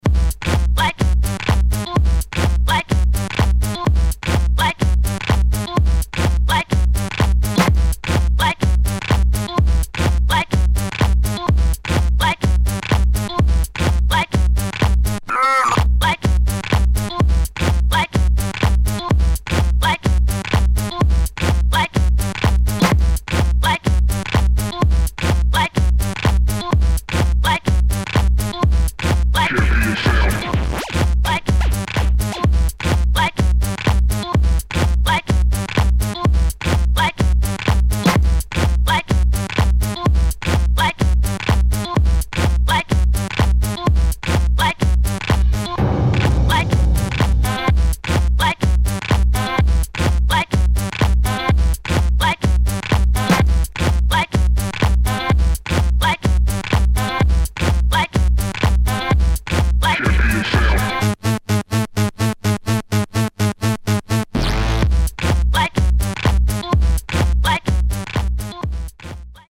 [ HOUSE | ELECTRO ]